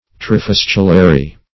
Search Result for " trifistulary" : The Collaborative International Dictionary of English v.0.48: Trifistulary \Tri*fis"tu*la*ry\, a. [Pref. tri- + fistula, fistular.] Having three pipes.